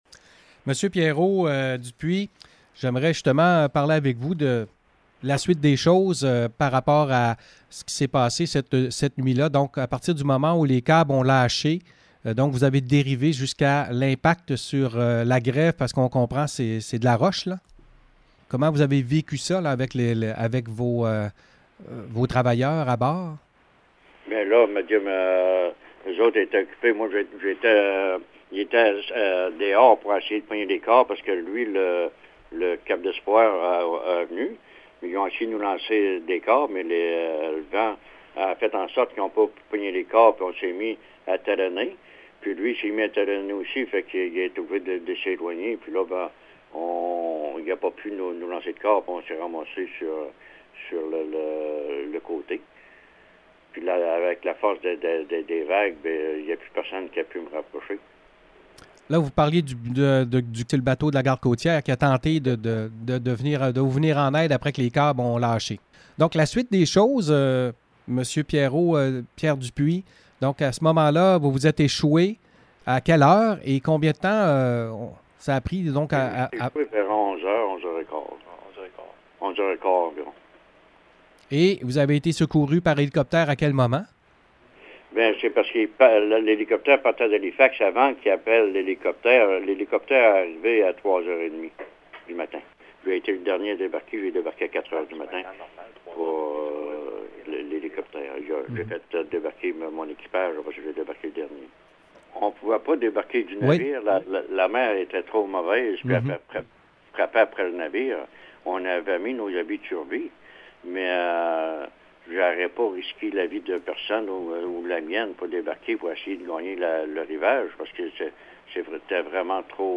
Entrevues avec les capitaines deuxième partie: